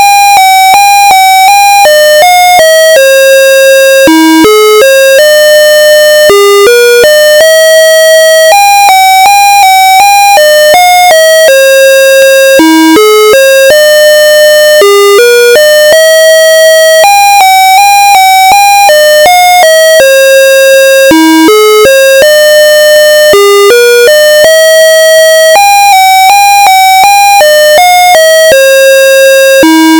Sounds a bit like Für Elise by Ludwig van Beethoven.
Sample Rate: 54000 Hz
Channels: 1 (mono)